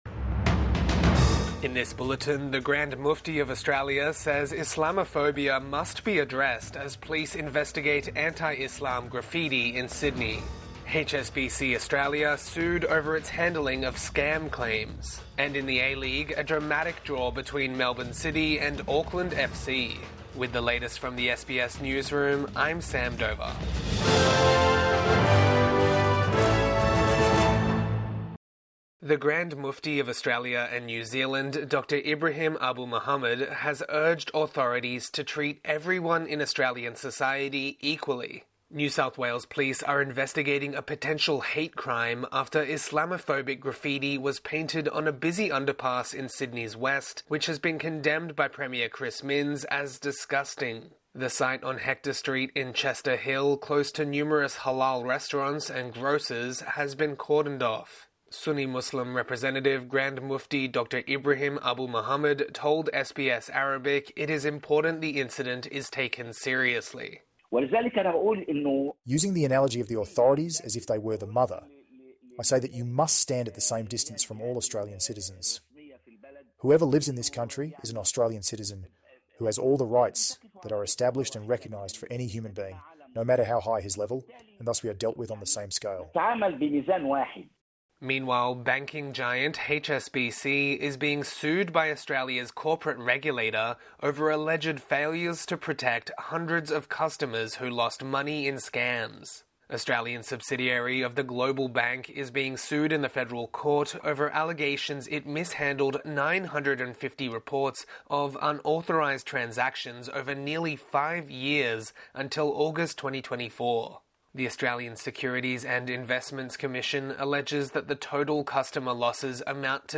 Midday News Bulletin 16 December 2024